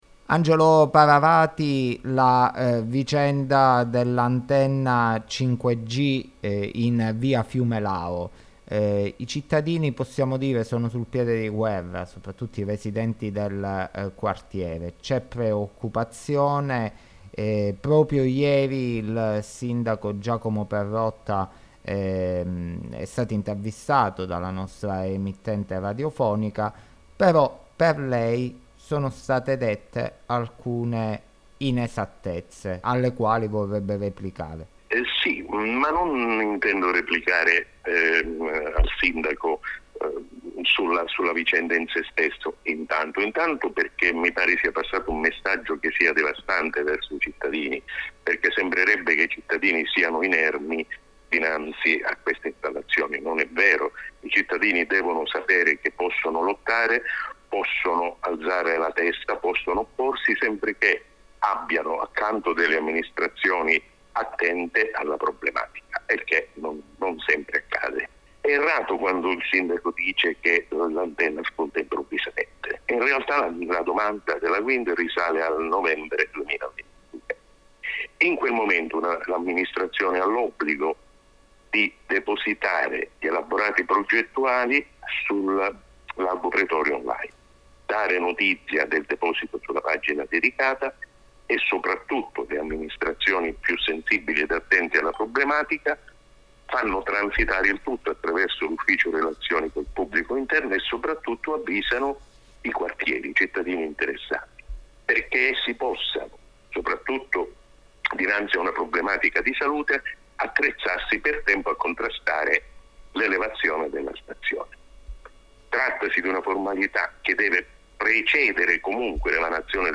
Intervista al consigliere di minoranza Angelo Paravati - Radio Digiesse | 93.4 E 95.3 FM